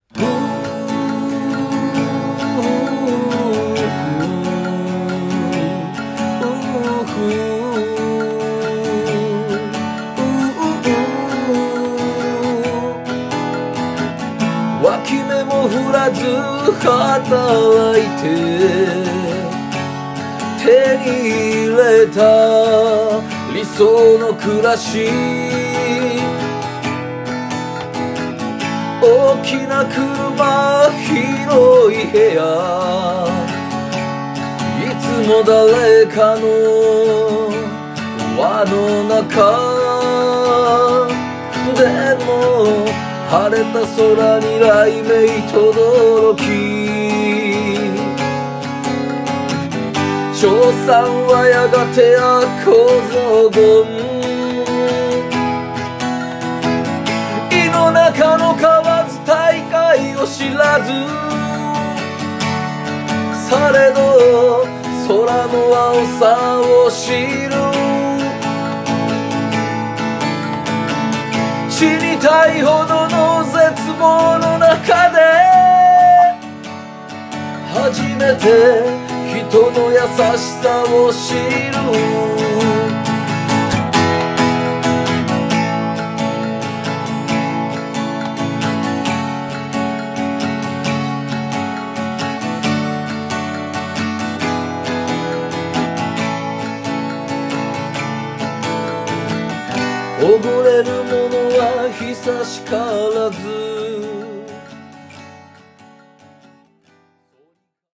□ご本人様ご用意 – 参考用の弾き語り音源